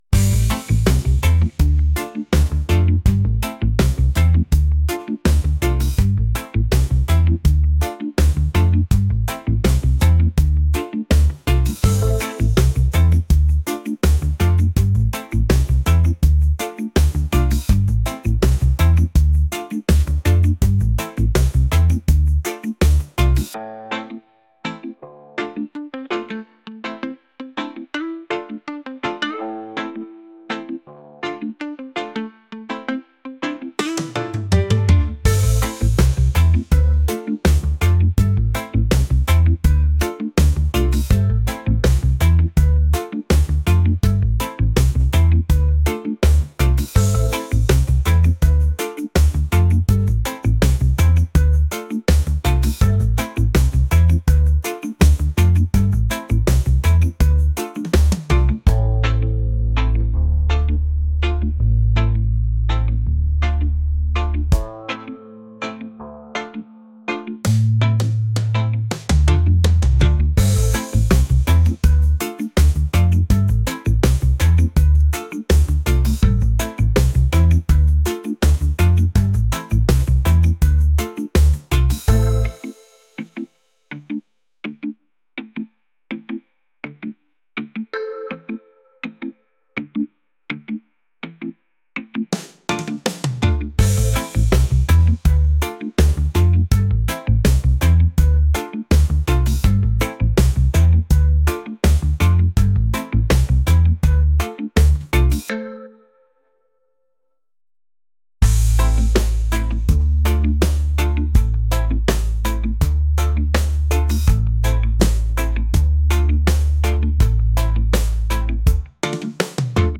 reggae | lounge